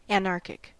Uttal
Synonymer anarchist anarchical Uttal US UK: IPA : /ænˈɑː.kɪk/ IPA : /əˈnɑː.kɪk/ US: IPA : /ænˈɑɹ.kɪk/ IPA : /əˈnɑɹ.kɪk/ Ordet hittades på dessa språk: engelska Ingen översättning hittades i den valda målspråket. Liknande ord anarchism anarchy Definitioner Adjektiv Relating to, supporting, or likely to cause anarchy .